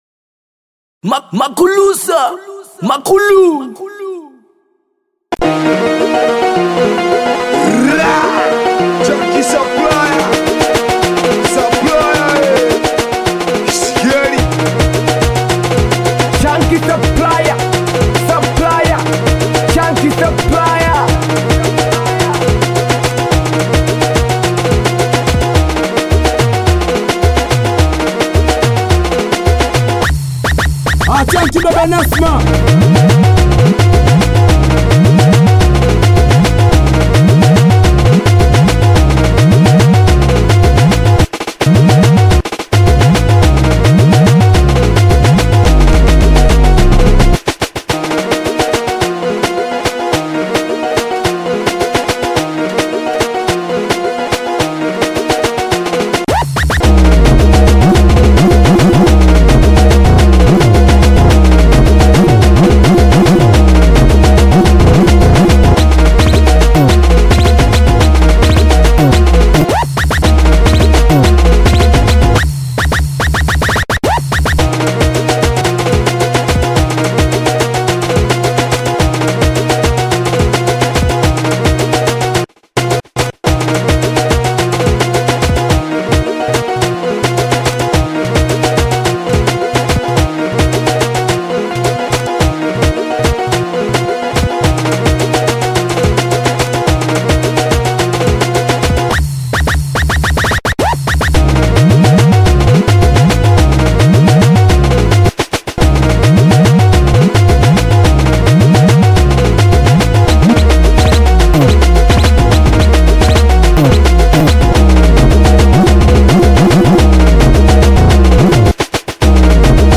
BITI SINGELI